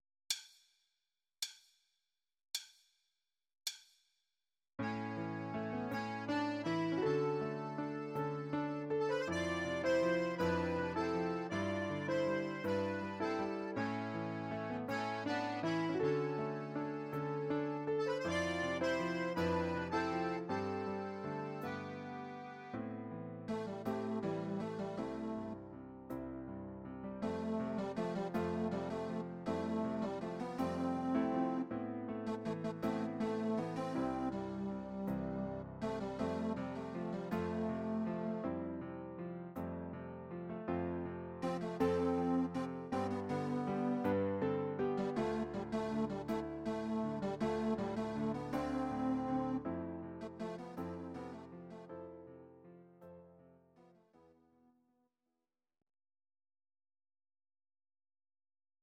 These are MP3 versions of our MIDI file catalogue.
Please note: no vocals and no karaoke included.
Your-Mix: 1990s (6888)